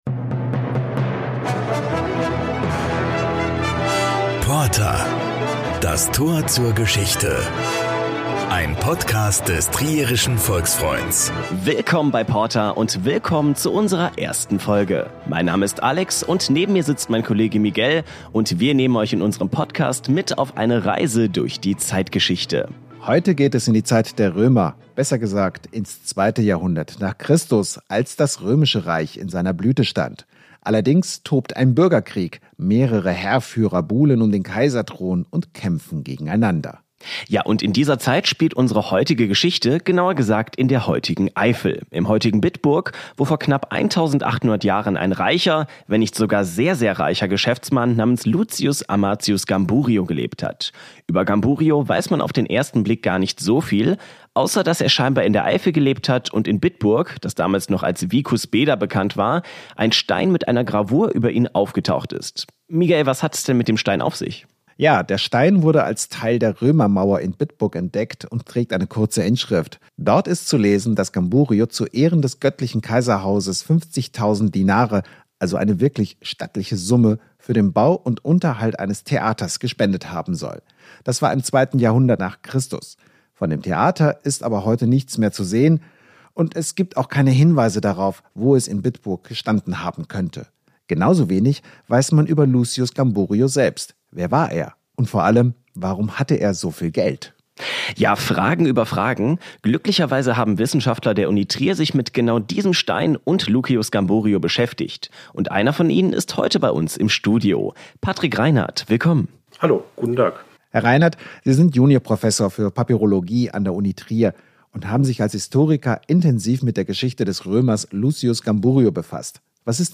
Was wissen wir heute noch durch archäologische Funde über ihn? Auf Spurensuche mit einem Historiker der Uni Trier.